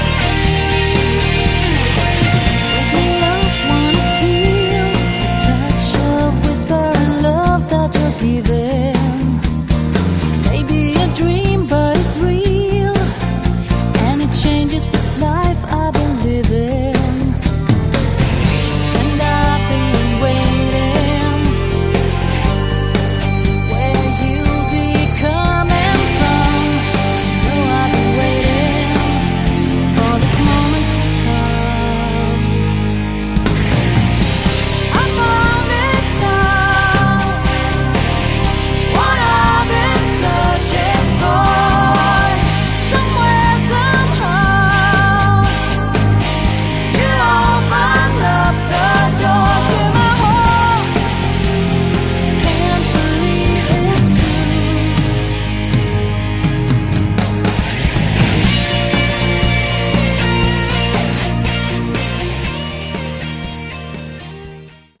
Category: AOR
guitar, keyboards, backing vocals
bass, drums